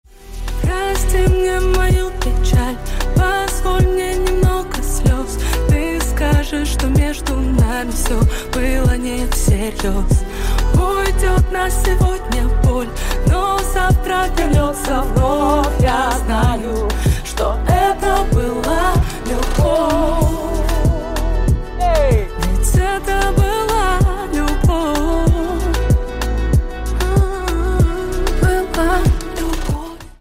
Грустные Рингтоны
Поп Рингтоны